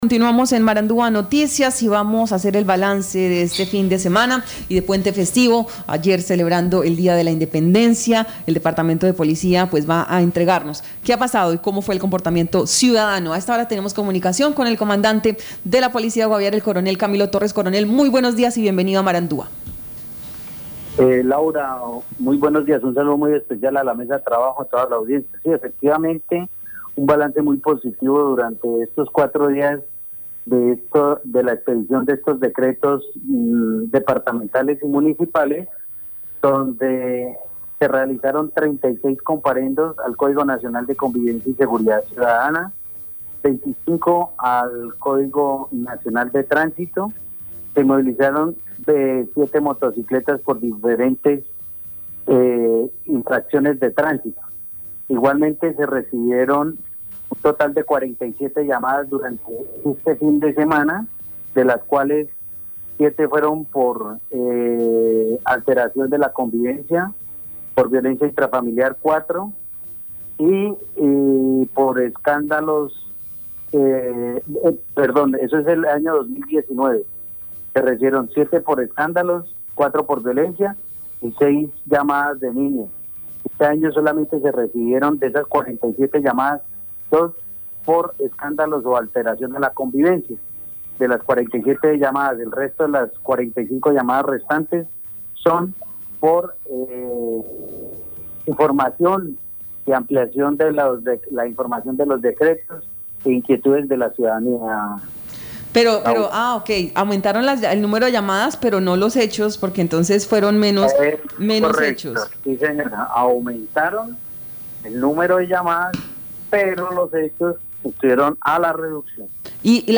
Escuche al Coronel Camilo Torres, comandante de Policía Guaviare.